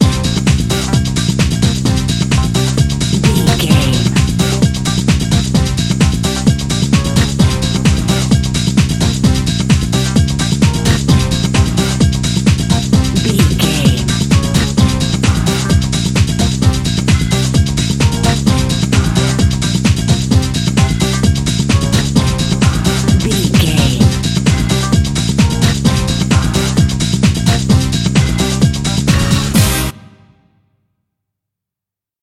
Aeolian/Minor
synthesiser
drum machine
Eurodance